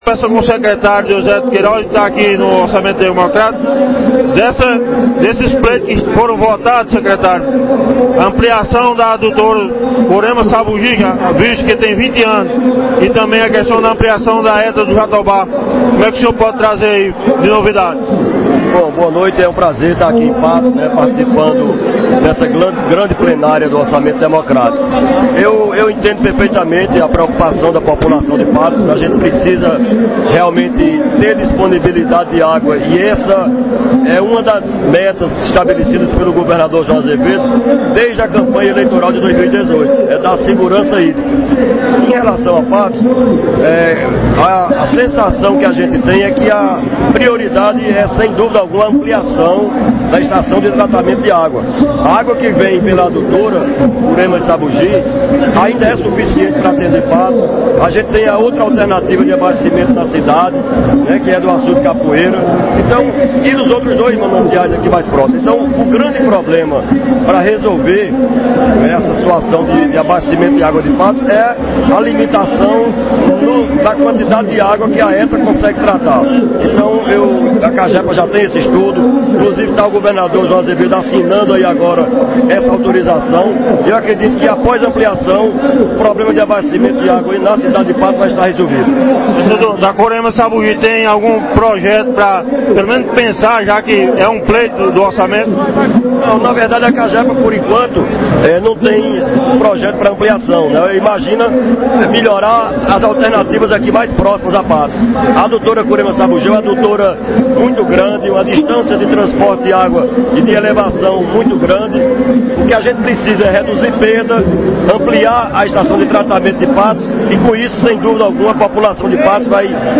Secretário de Recursos Hídricos da Paraíba fala sobre nova estação de tratamento para Patos. Ouça